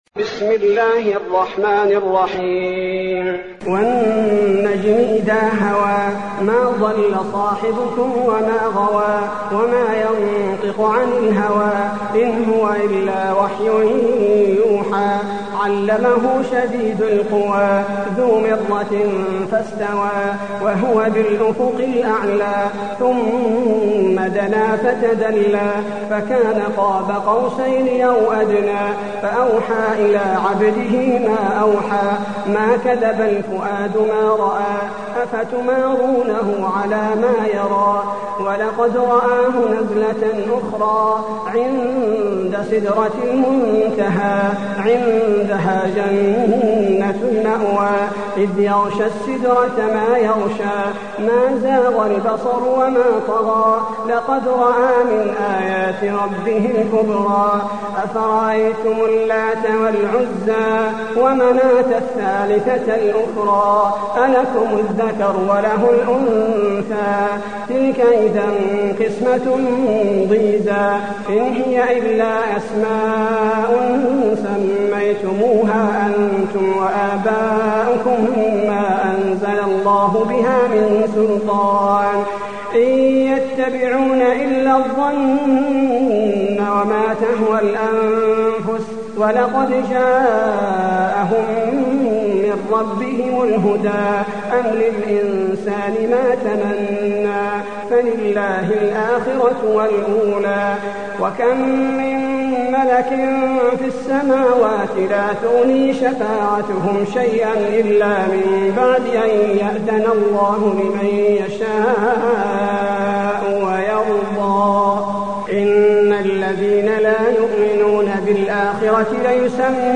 المكان: المسجد النبوي النجم